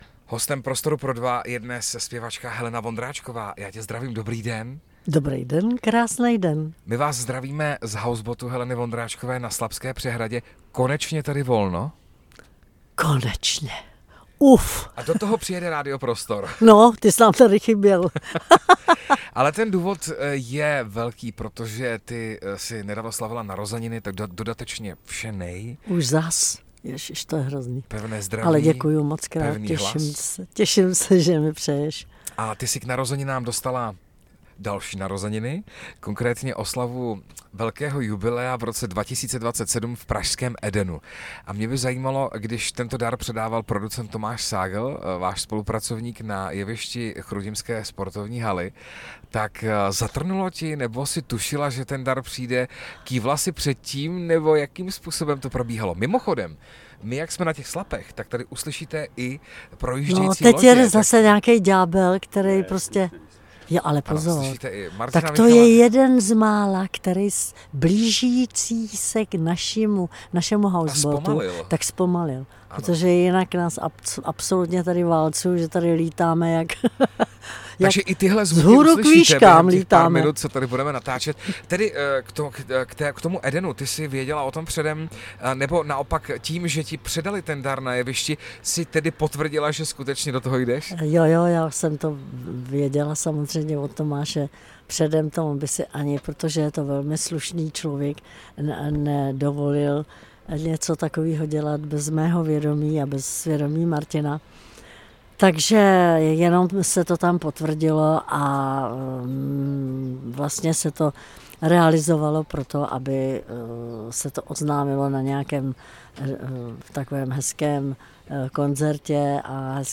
Dalším hostem Prostoru pro dva byla zpěvačka Helena Vondráčková.